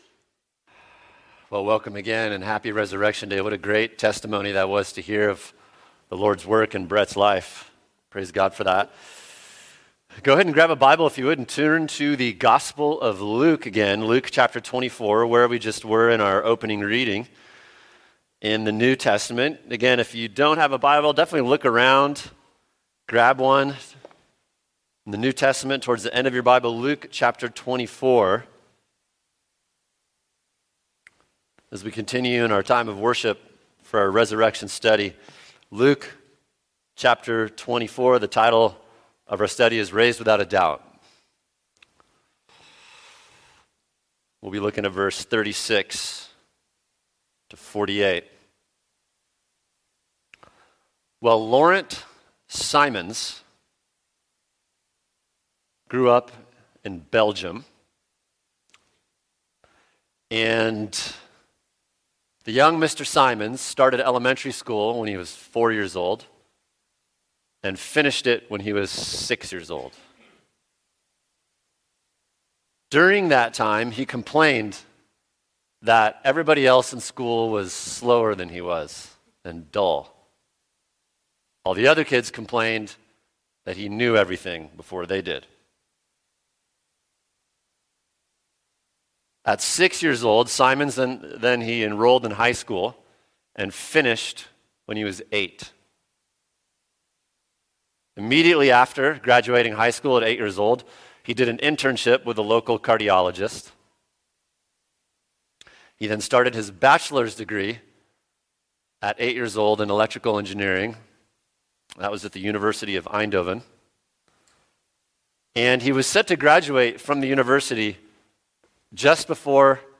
[sermon] Luke 24 Raised Without A Doubt | Cornerstone Church - Jackson Hole